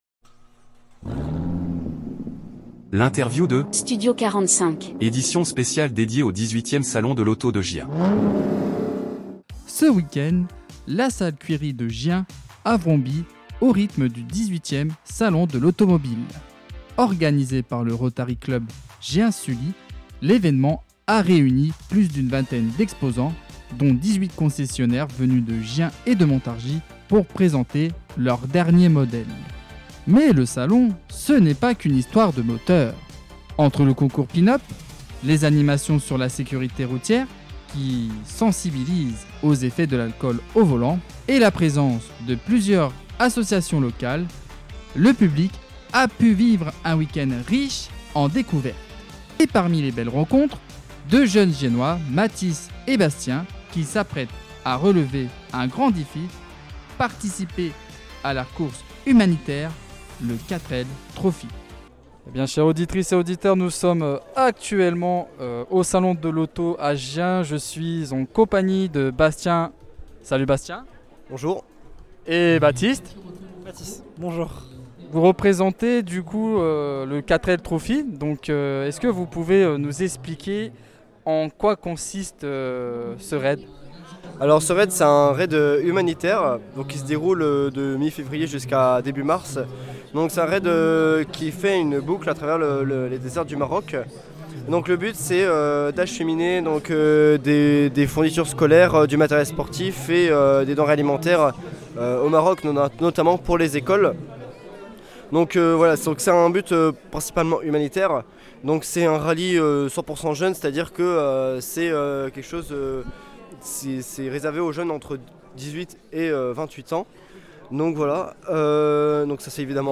Interview Studio 45 - édition spéciale Salon de l'Auto - Vertical 4L